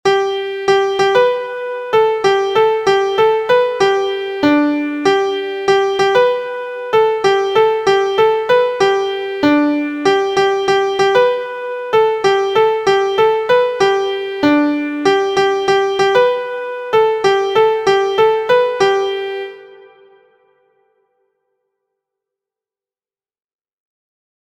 Introducing syncopation with sixteenth notes.
• Origin: French Folk Song
• Key: G Major
• Time: 2/4
• Form: AAAAaaaa